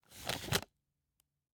take_wipe.ogg